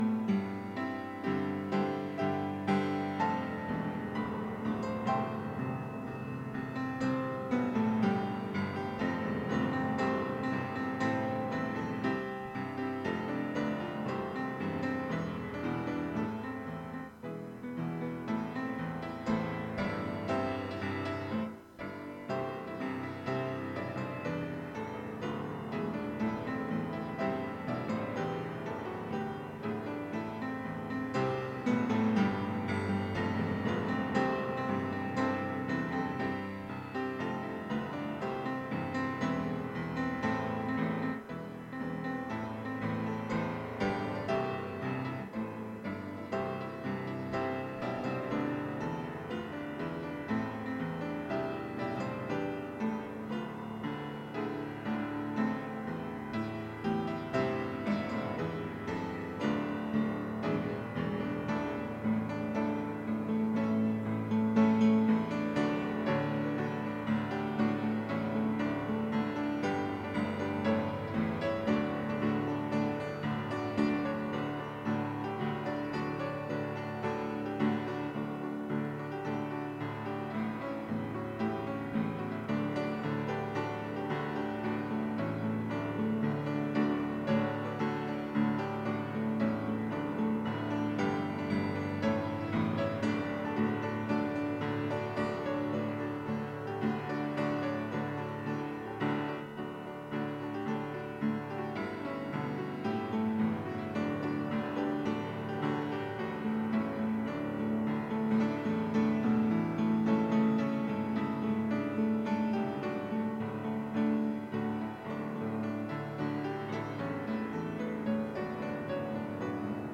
Matthew | Sermon Series | Dallasburg Baptist Church
April 28, 2024 (Sunday Morning Service)